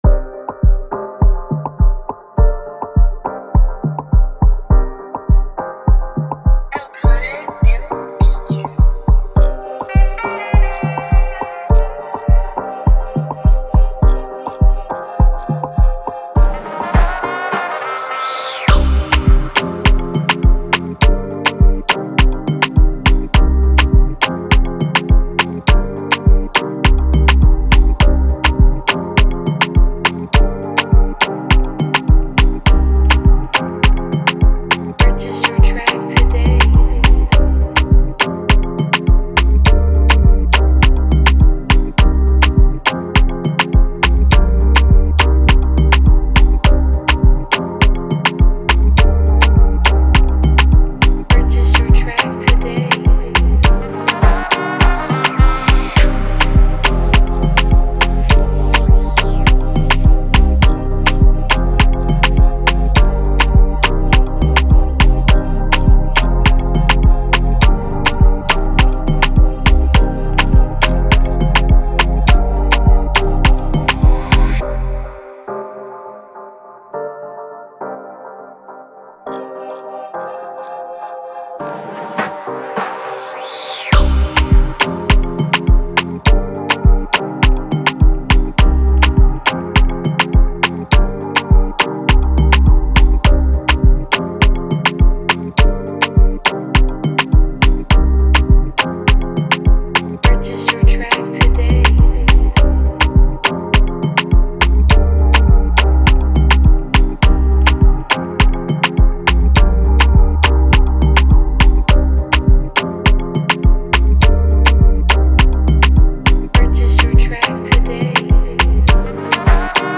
an Afrobeat instrumental
This Nigeria-type beat